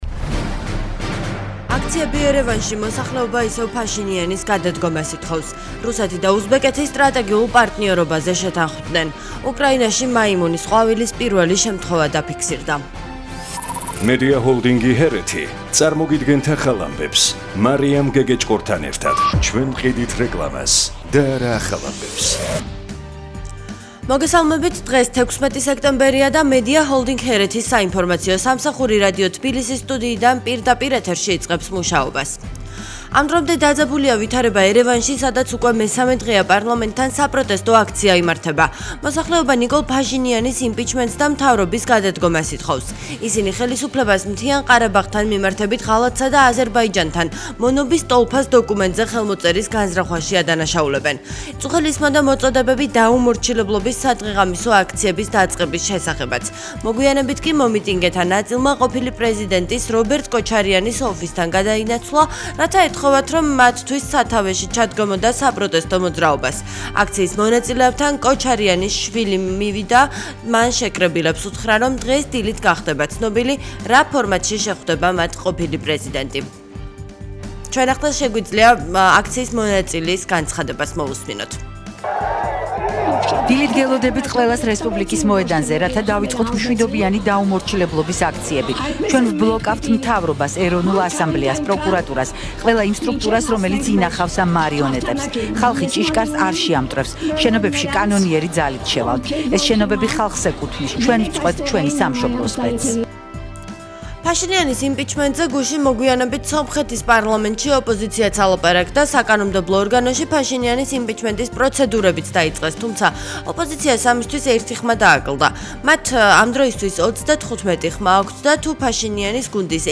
ახალი ამბები 09:00 საათზე